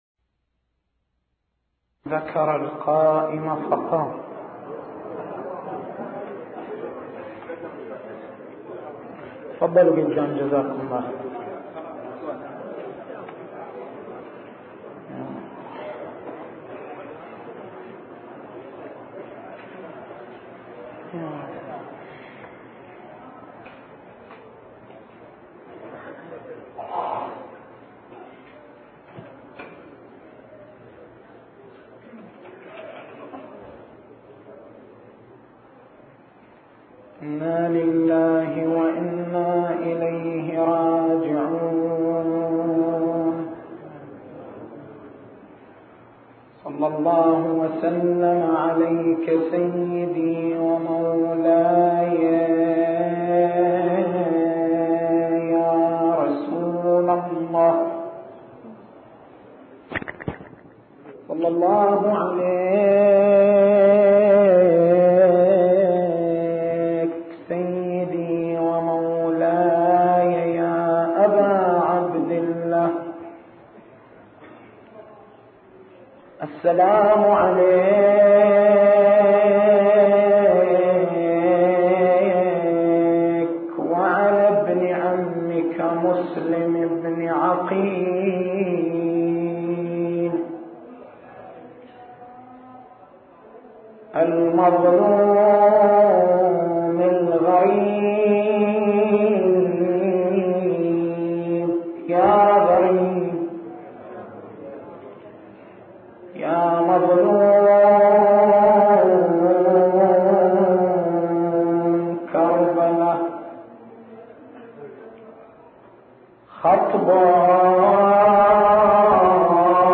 تاريخ المحاضرة: 04/01/1422 نقاط البحث: مفهوم المحكم والمتشابه في القرآن الكريم فلسفة وجود المتشابه في القرآن الكريم كيفية التعامل مع النصوص المتشابهة التسجيل الصوتي: تحميل التسجيل الصوتي: شبكة الضياء > مكتبة المحاضرات > محرم الحرام > محرم الحرام 1422